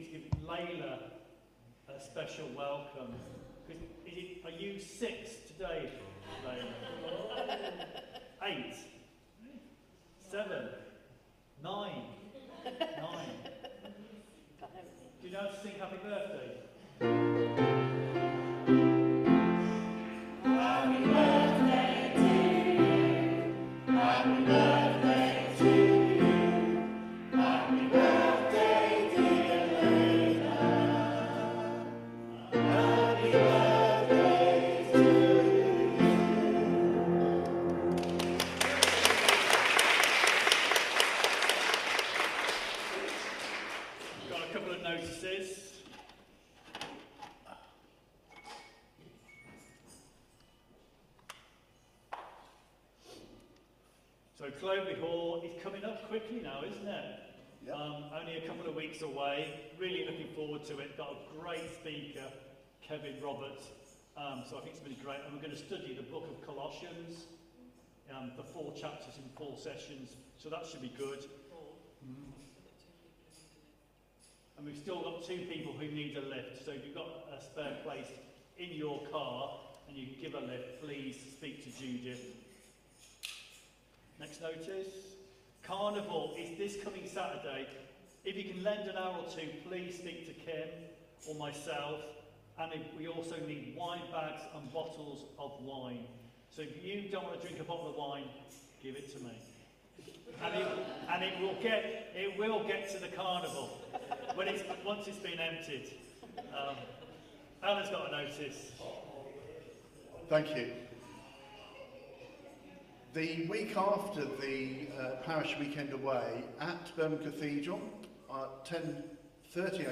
Media for Contemporary Worship on Sun 15th Jun 2025 11:00 Speaker